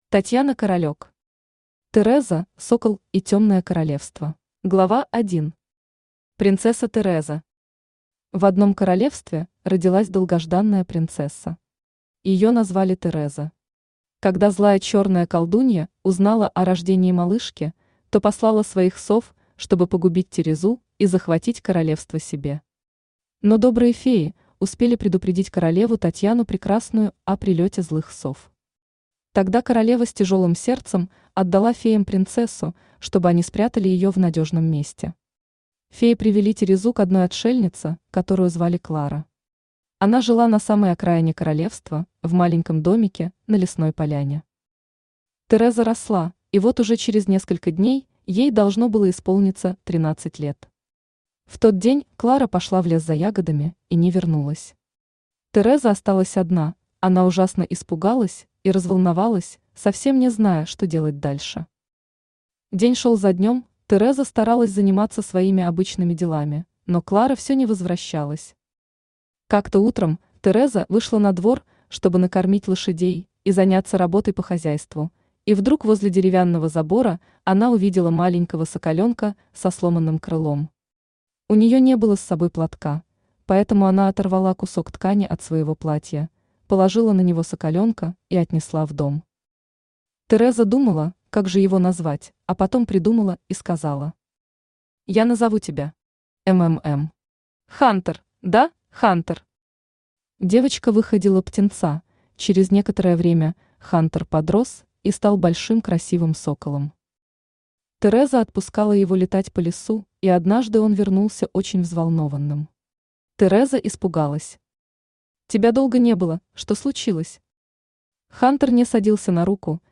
Читает: Авточтец ЛитРес
Аудиокнига «Тереза, сокол и Темное королевство».